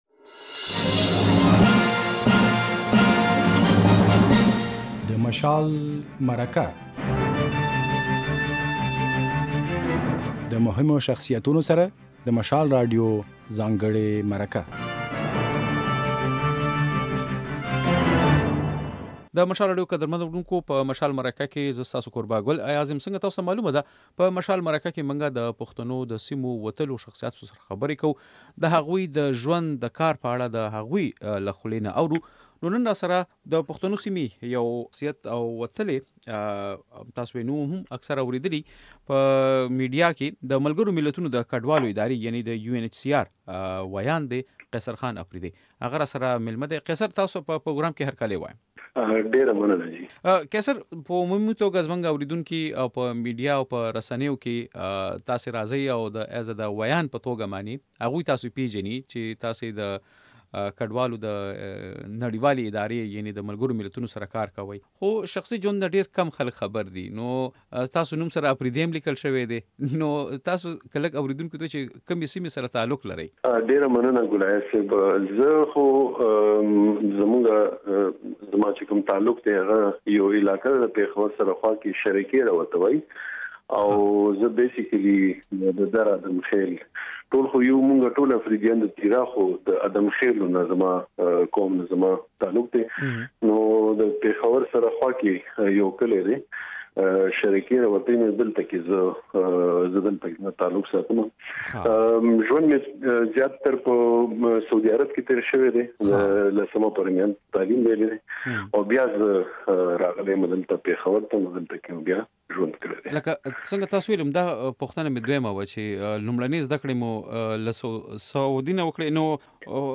په مشال مرکه کې مو دا ځل په اسلام اباد کې د ملګرو ملتونو د کډوالو ادارې